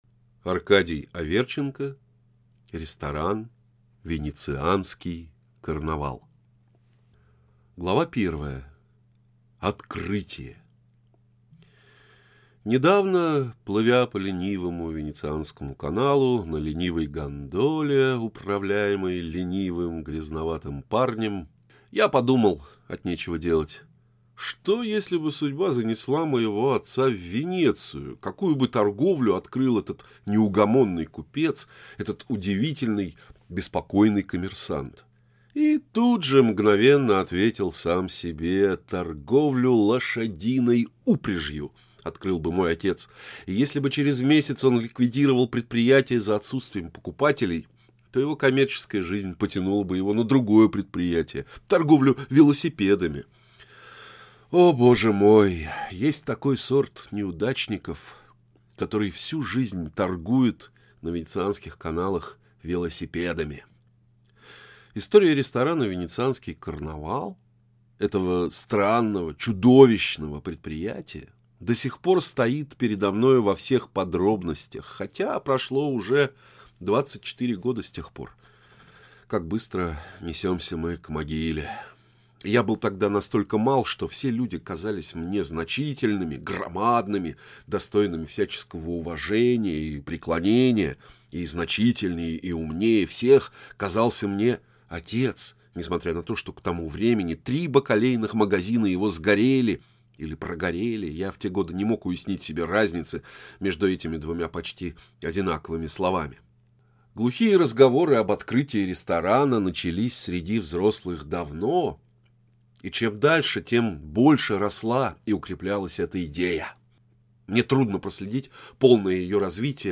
Аудиокнига Ресторан «Венецианский карнавал» | Библиотека аудиокниг